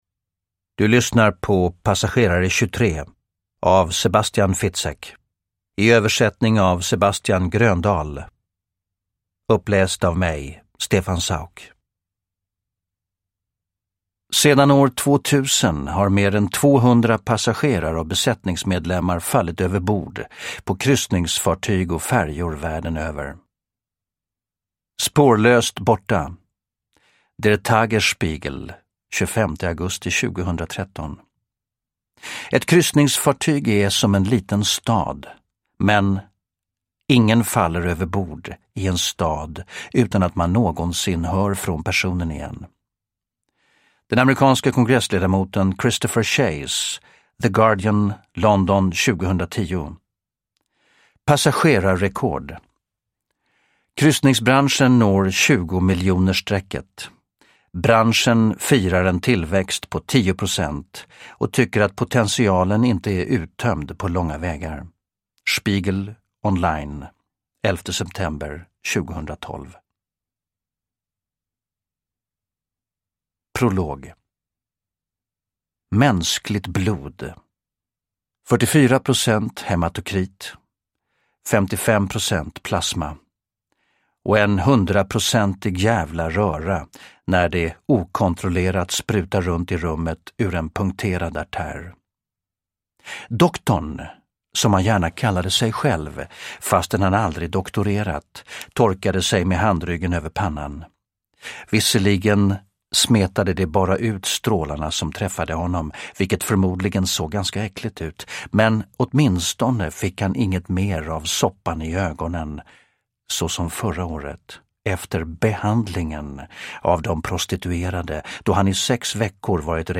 Passagerare 23 – Ljudbok – Laddas ner
Uppläsare: Stefan Sauk